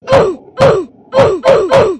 Five ground pounds are being heard in a "1-2-123" rhythm type. Each pound plays the Roblox death sound "Oof!" in addition.
quintiple-pound.mp3